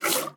Minecraft Version Minecraft Version 1.21.5 Latest Release | Latest Snapshot 1.21.5 / assets / minecraft / sounds / mob / mooshroom / milk3.ogg Compare With Compare With Latest Release | Latest Snapshot
milk3.ogg